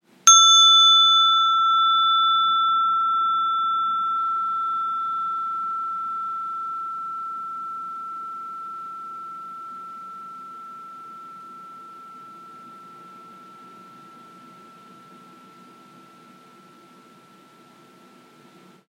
1. Klankschaal Blauw – E 6 – 1334 hertz
Deze handzame klankschaal heeft een Ø 7 cm en is slechts 4 cm hoog. Luister naar het heldere zingen van dit prachtige schaaltje. Een ontwakend geluid dat door elke afleiding heen klinkt.